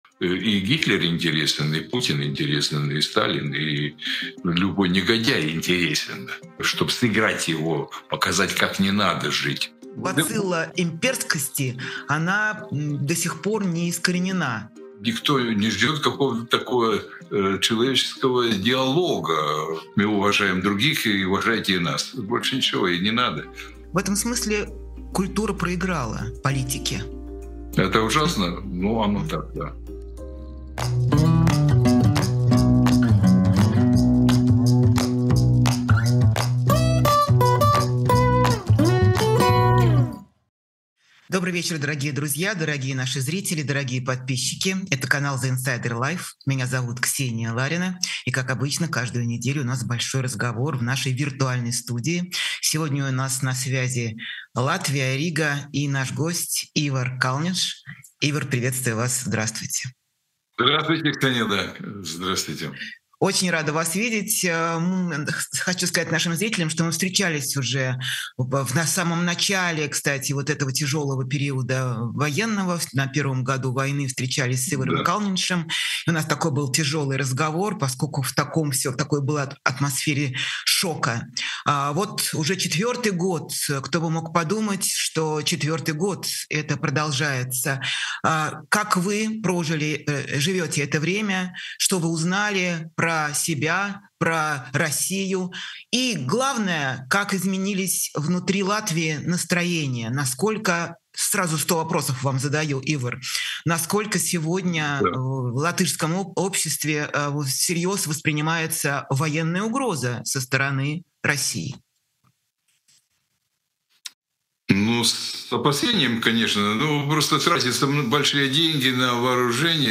Эфир ведёт Ксения Ларина
Гость — актер Ивар Калныньш.